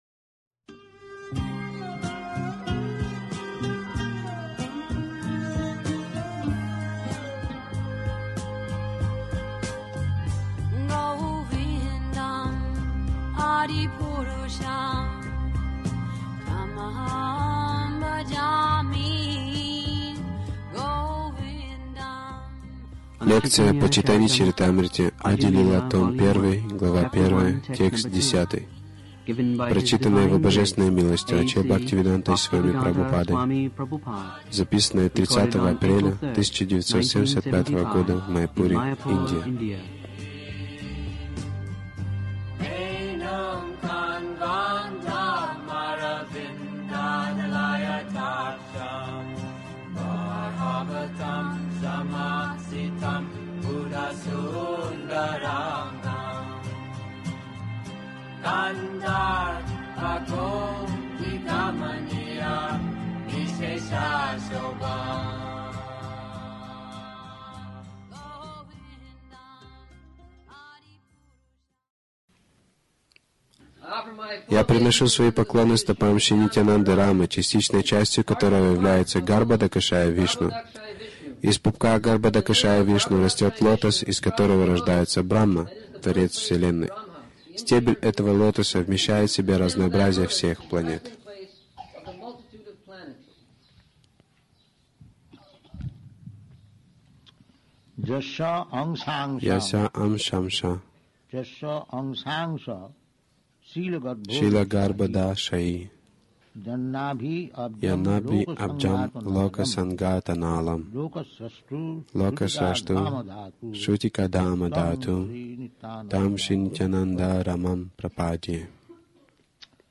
Милость Прабхупады Аудиолекции и книги 03.04.1975 Чайтанья Чаритамрита | Маяпур ЧЧ Ади-лила 01.010 Загрузка...